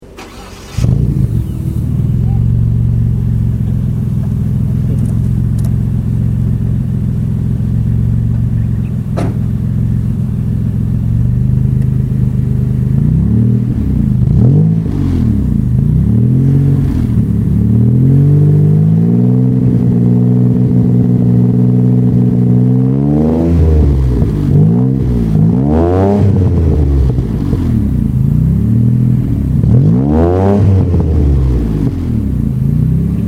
Car Sounds- Mitsubishi 3000 GT Engine Hum.mp3